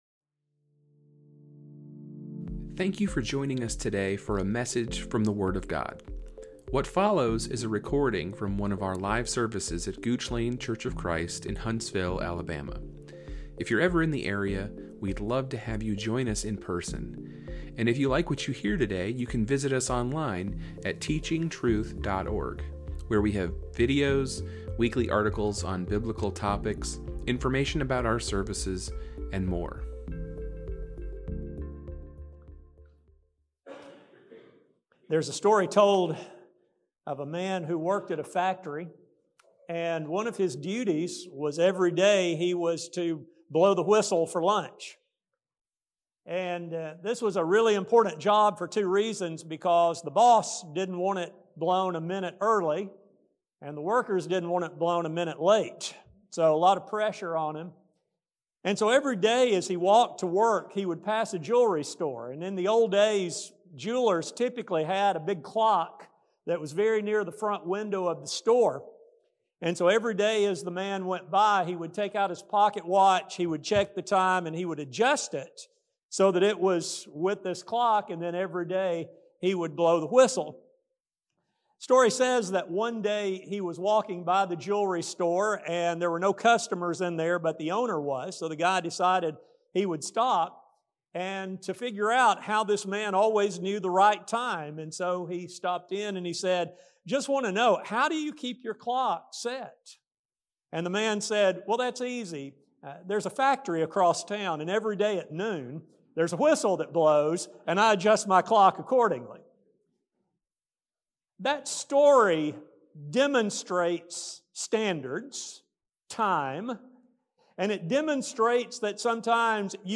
This study will explore the strengths of this type of worldview as well as the dangers posed by its rejection. A sermon